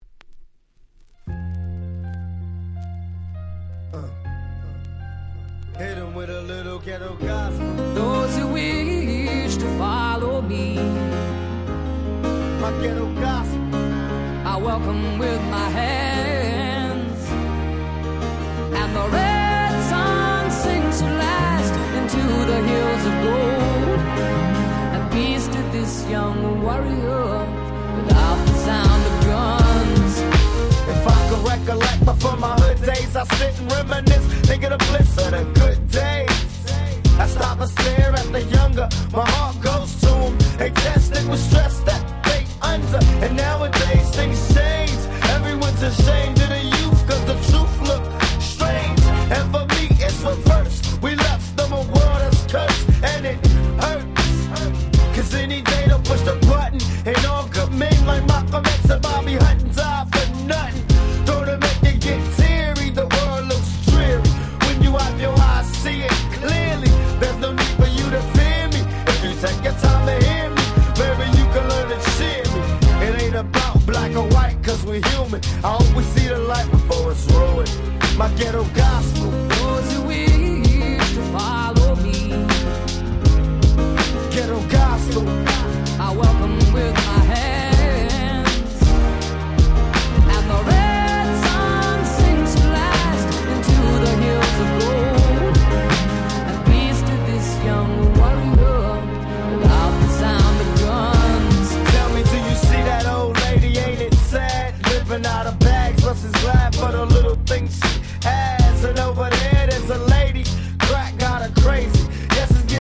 04' Super Hit Hip Hop !!
ウエストコーストヒップホップ G-Rap ギャングスタラップ